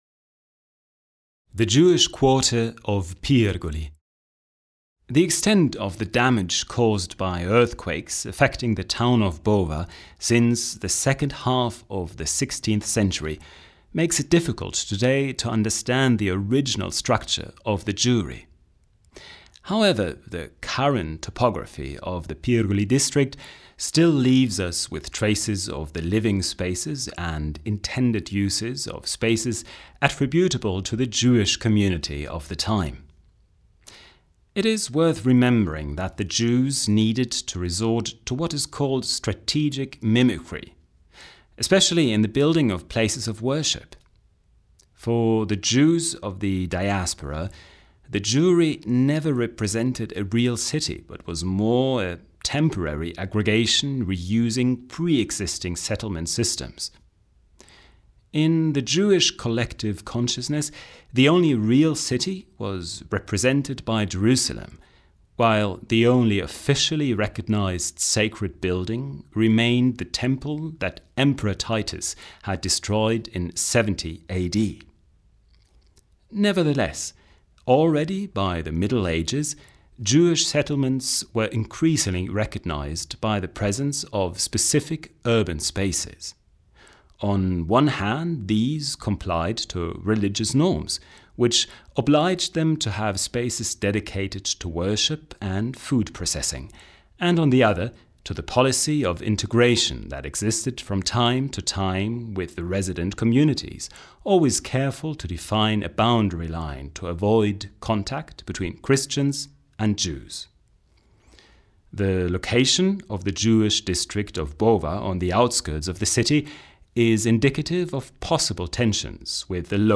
HOME AUDIOGUIDA DELLA GIUDECCA